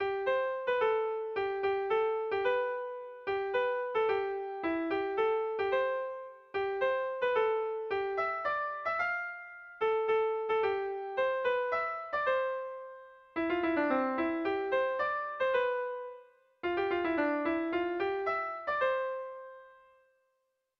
Irrizkoa
Lau puntuko berdina, 9 silabaz
ABDE